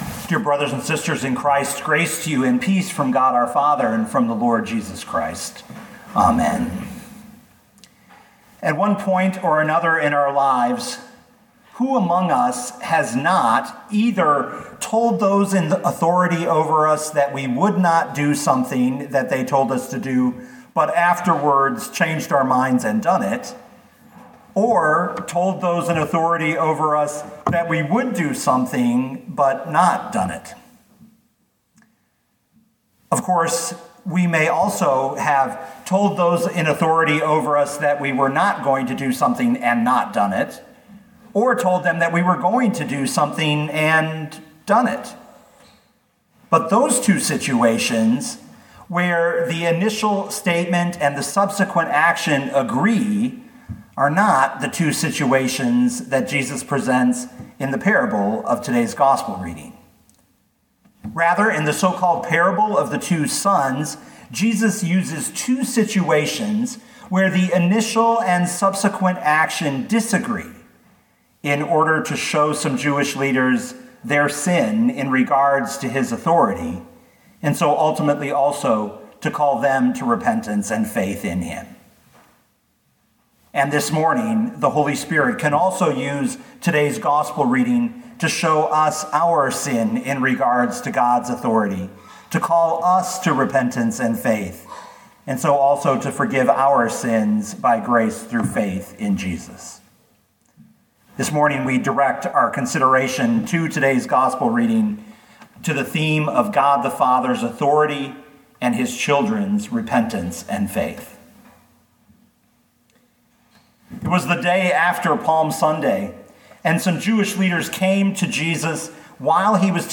2020 Matthew 21:23-32 Listen to the sermon with the player below, or, download the audio.